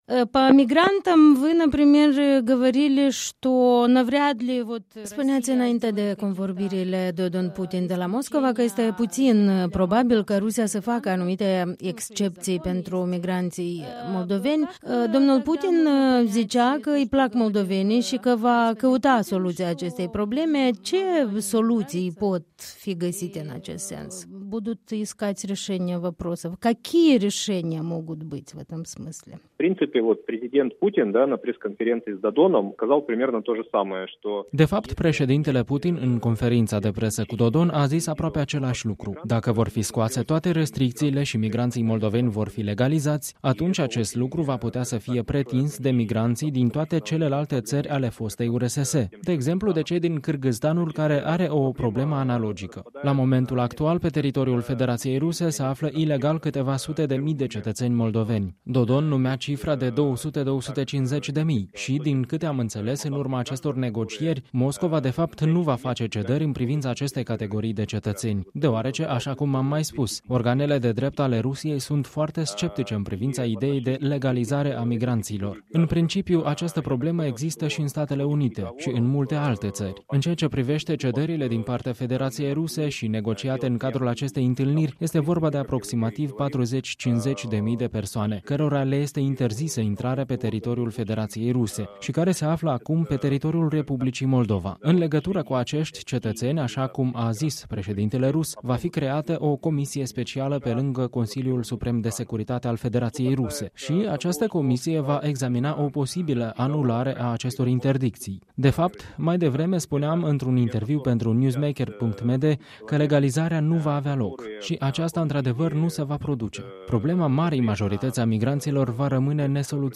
Interviu cu un expert rus, docent al Institutului de Economie al Universităţii Lomonosov din Moscova.